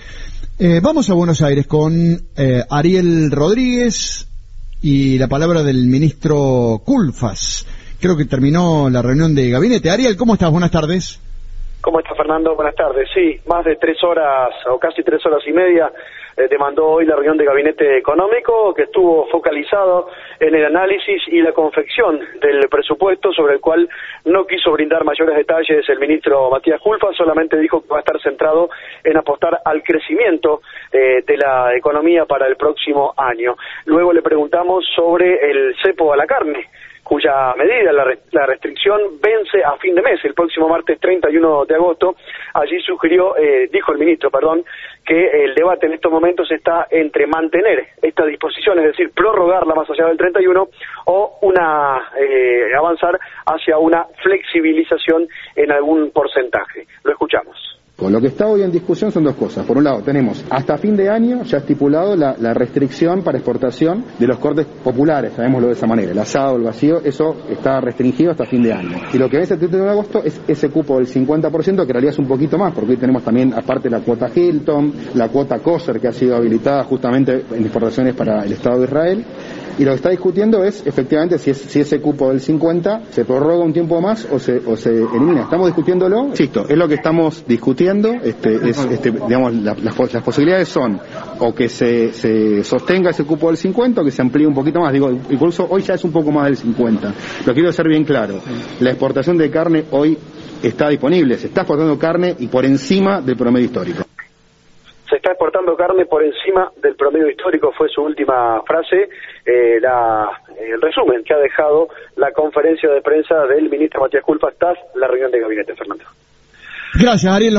“Estamos analizando si continúa, si se amplía un poco más el 50% o si se da alguna modificación”, expresó Kulfas en declaraciones a la prensa luego de la reunión del Gabinete Económico, que duró más de tres horas.